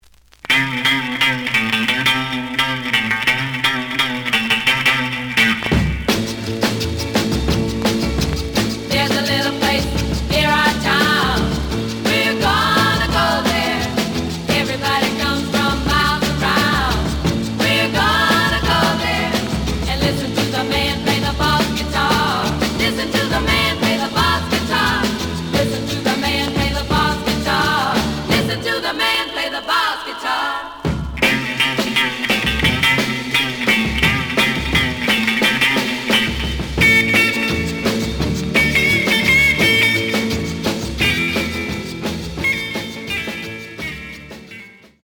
The audio sample is recorded from the actual item.
●Genre: Rhythm And Blues / Rock 'n' Roll
A side plays good.